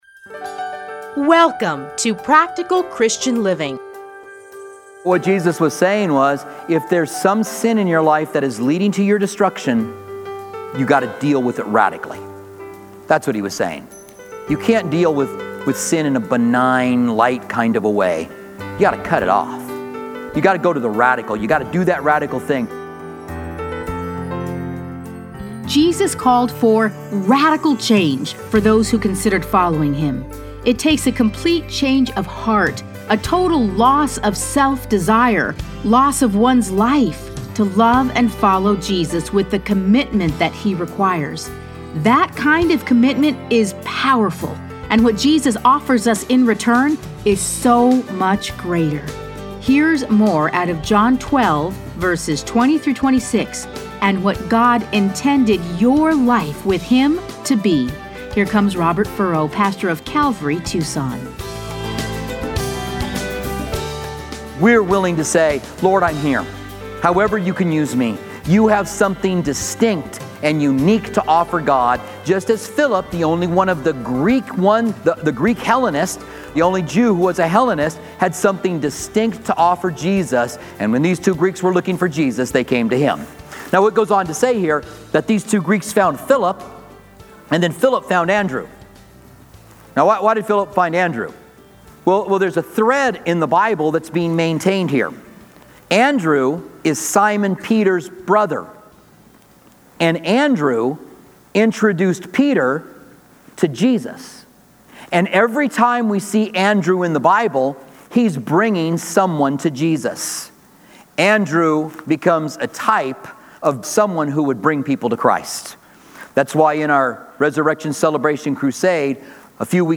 Listen to a teaching from John 12:20-26.